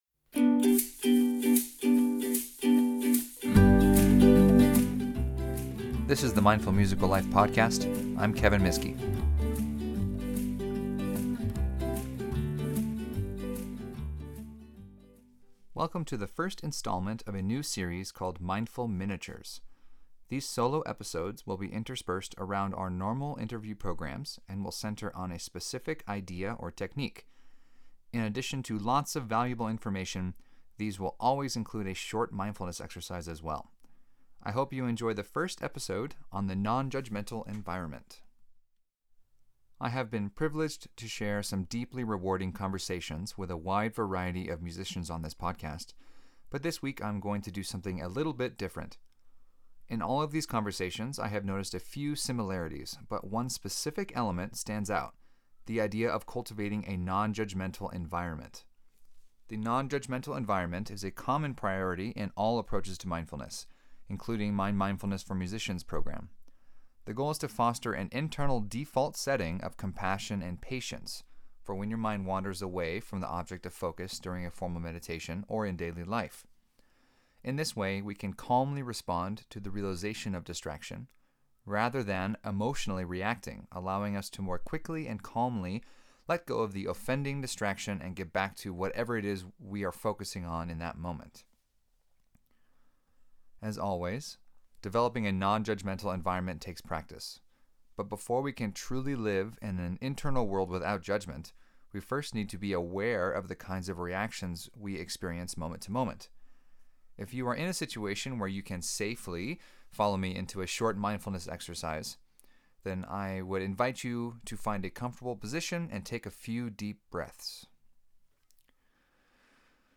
These solo episodes will be interspersed around the normal interview programs, and will center on a specific idea or technique. In addition to lots of valuable information, these will always include a short mindfulness exercise as well.